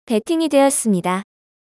voice_bet_complete.mp3